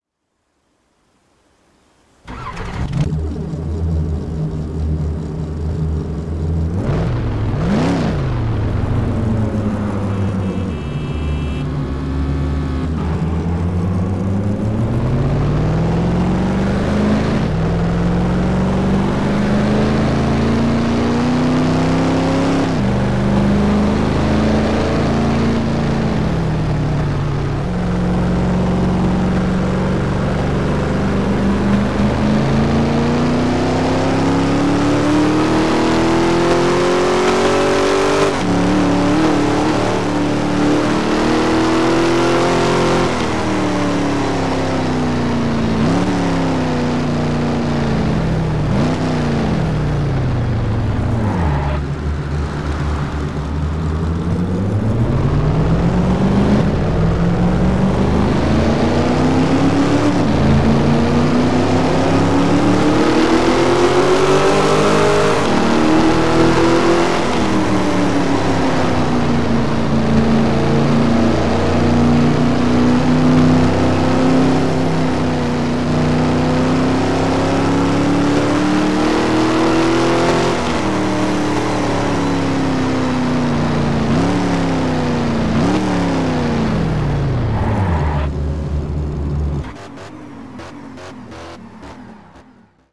- Ford GT
- Ford Mustang